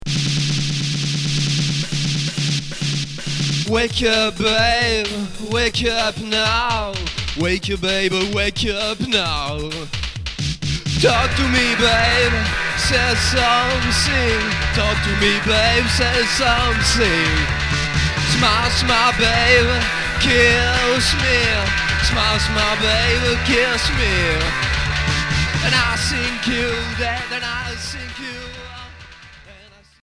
Rock expérimental